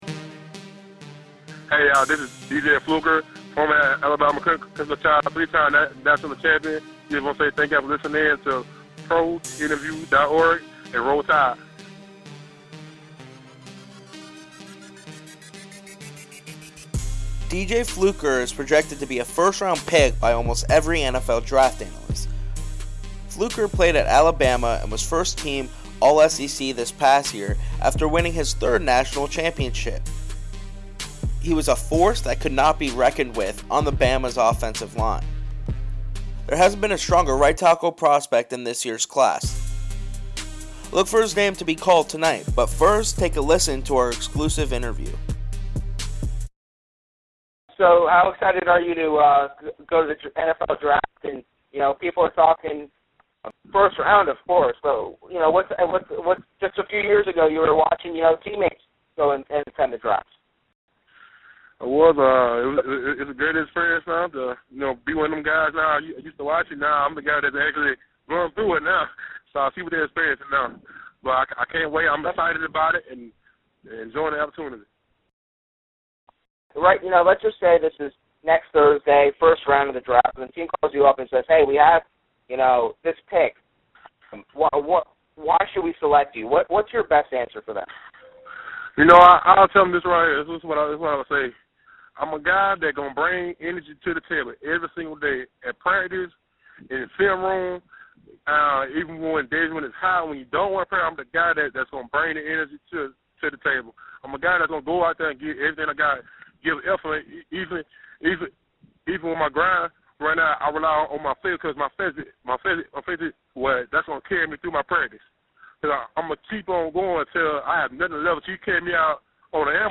Current Chargers OL, Former Alabama and Draft Prospect, D.J. Fluker Interview
dj-fluker-interview.mp3